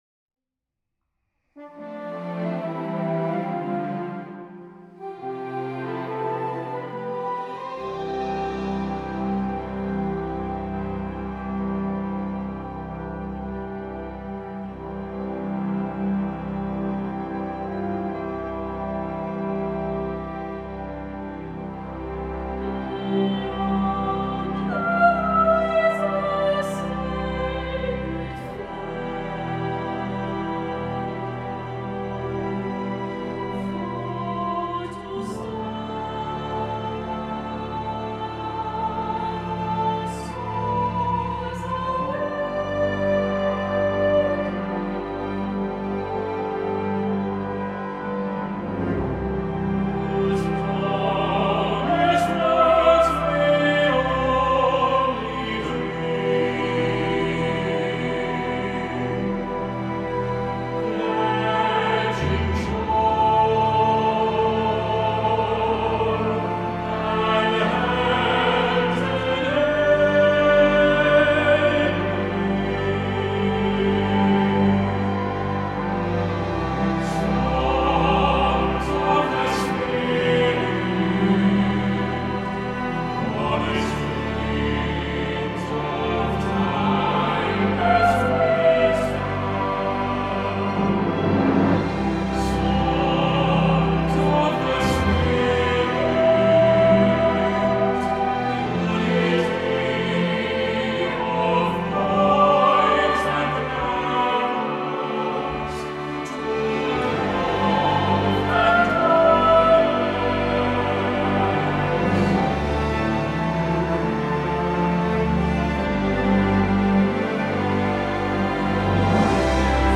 Scored primarily for SATB
accompanied by optional piano, organ and strings.